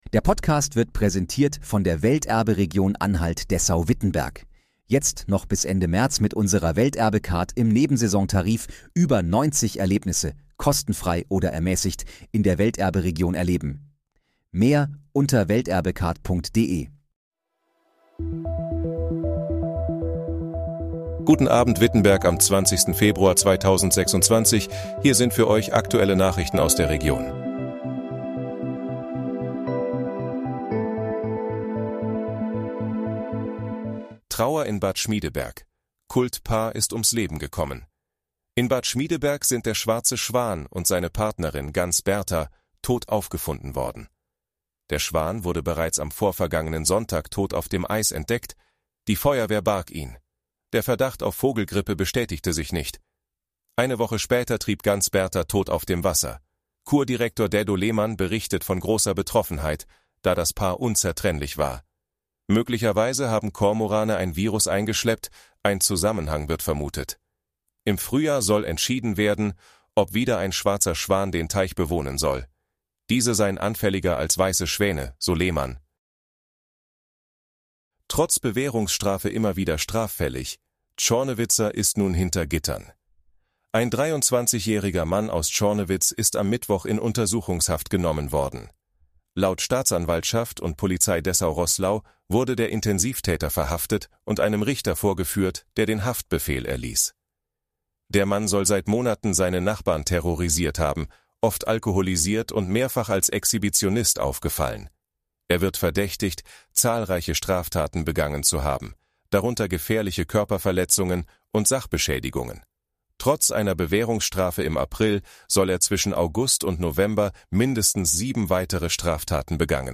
Guten Abend, Wittenberg: Aktuelle Nachrichten vom 20.02.2026, erstellt mit KI-Unterstützung
Nachrichten